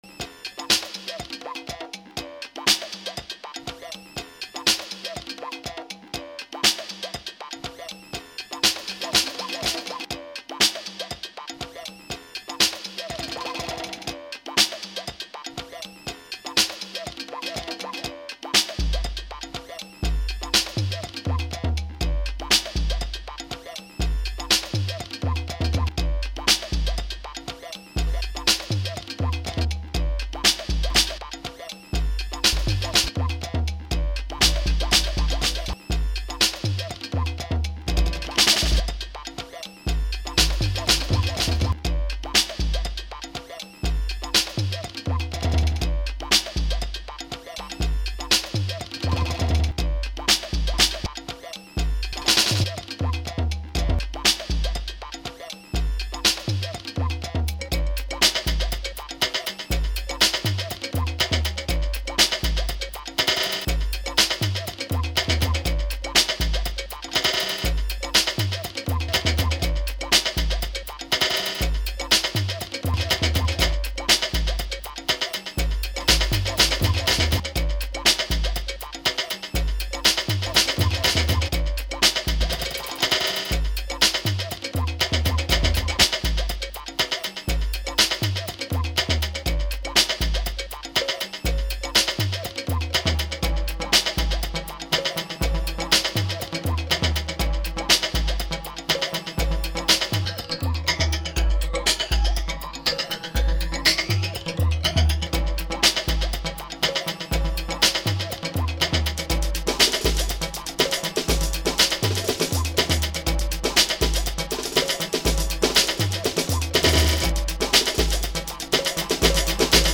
Samba-like rhythms